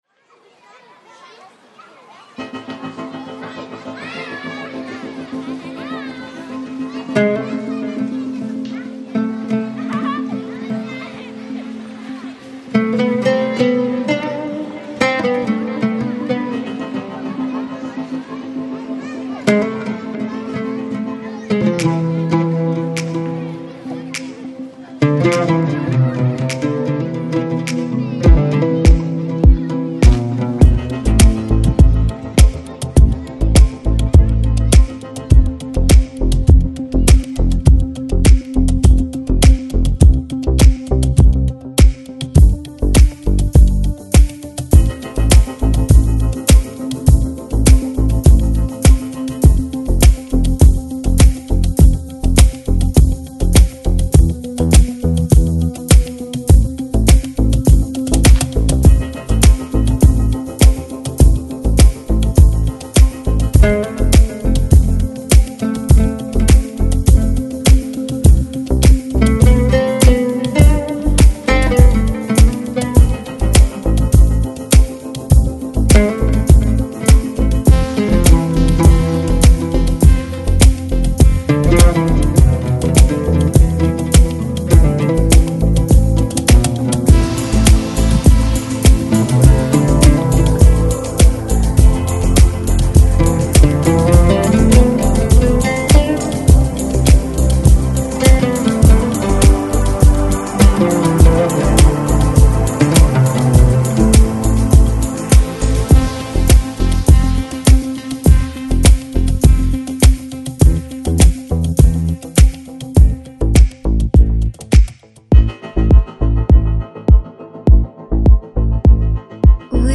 Жанр: Downtempo, Lounge, Chill Out